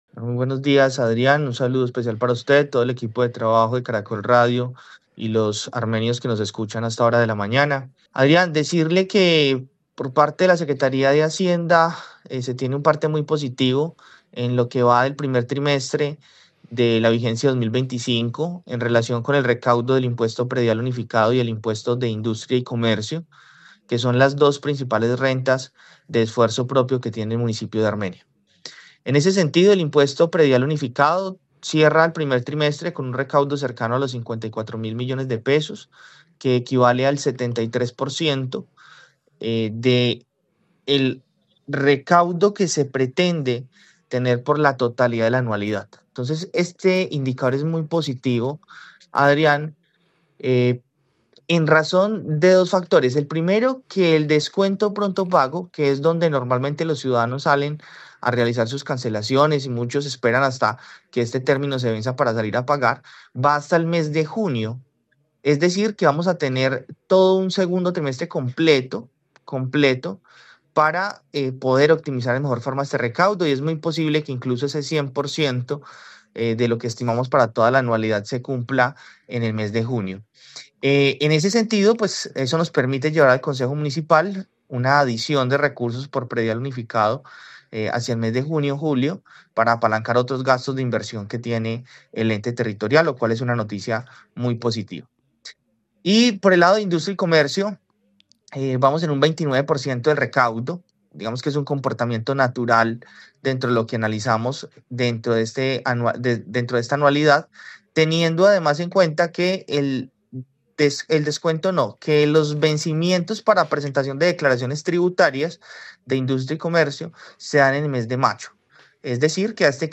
Yeison Andrés Pérez, secretario de hacienda en Armenia
En Caracol Radio Armenia hablamos con el secretario de hacienda, Yeison Andrés Pérez que explicó “por parte de la Secretaría de Hacienda se tiene un parte muy positivo en lo que va del primer trimestre de la vigencia 2025 en relación con el recaudo del impuesto predial unificado y el impuesto de industria y comercio, que son las dos principales rentas de esfuerzo propio que tiene el municipio de Armenia.